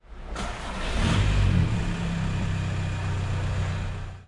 购物中心停车场 " 汽车启动
描述：大停车场内汽车马达启动的声音（嘈杂和混响的环境）.